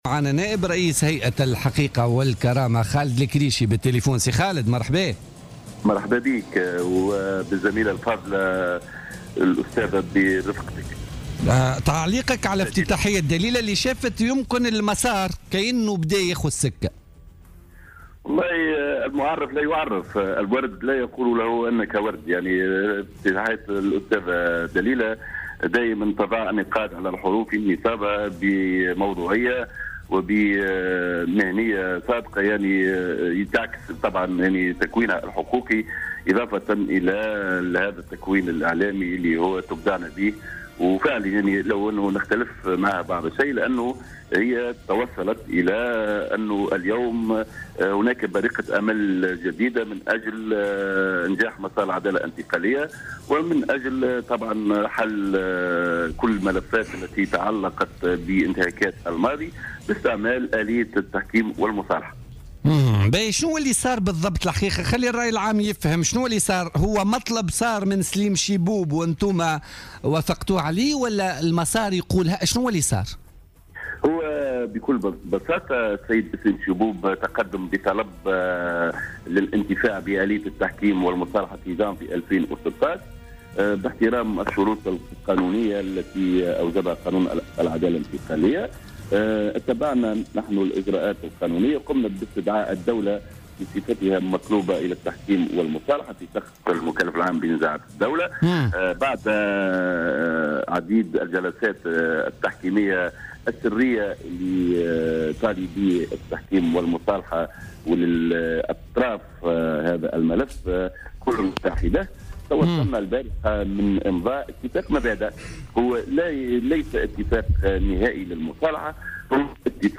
Khaled Krichi, vice-président de l'Instance Vérité et Dignité (IVD) était l'invité ce vendredi 6 mai 2016 de l'émission Politica sur Jawhara Fm.